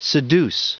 Prononciation du mot seduce en anglais (fichier audio)
Prononciation du mot : seduce